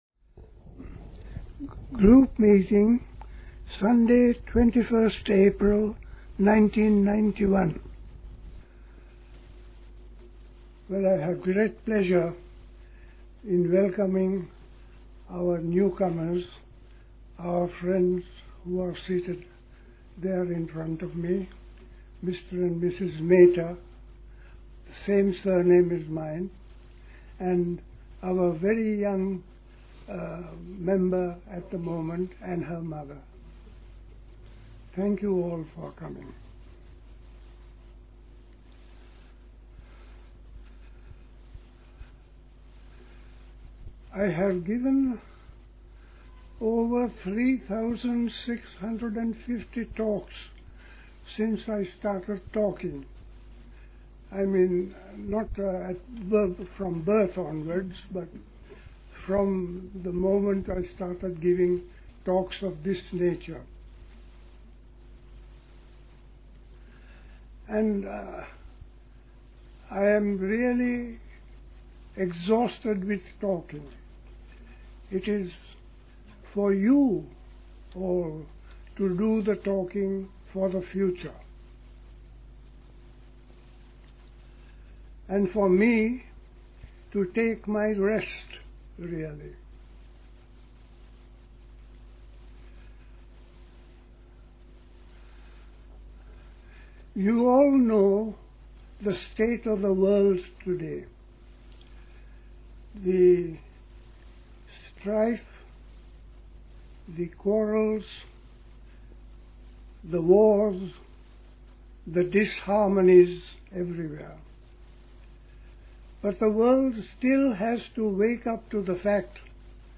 at Dilkusha, Forest Hill, London on 21st April 1991